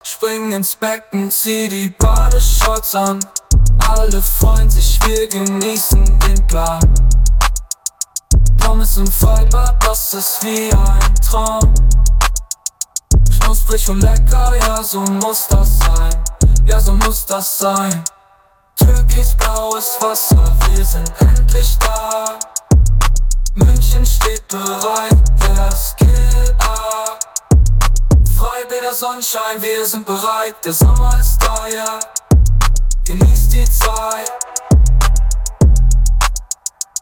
Mai 2024: Freibad-Deutschrap
Sogar das Eingeben von Lyrics ist möglich, die die KI dann singt.
Freibad-Deutschrap.mp3